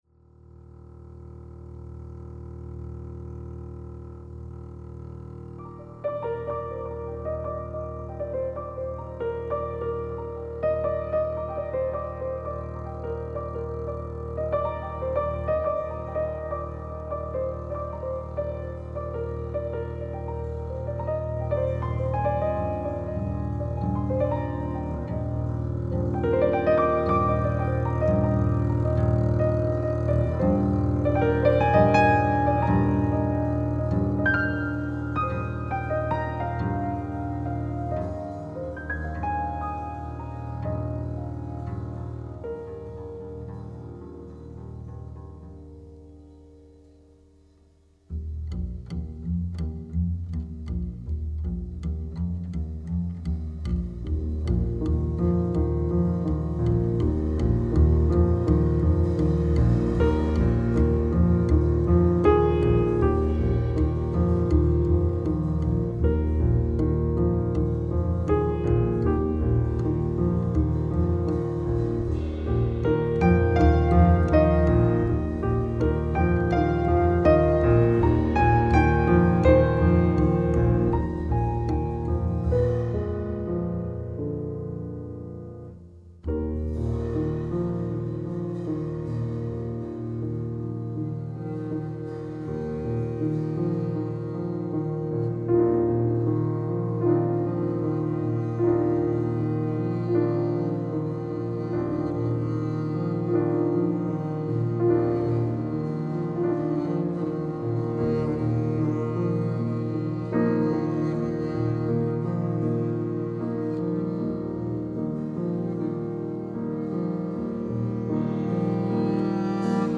original ambient piano trio music